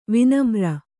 ♪ vinamra